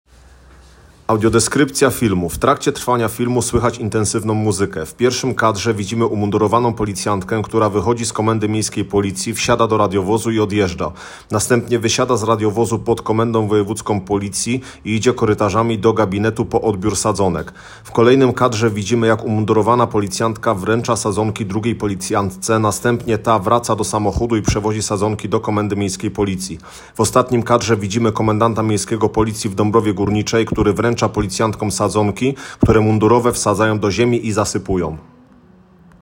Nagranie audio Sadzonki_drzew-audiodeskrypcja_filmu_.m4a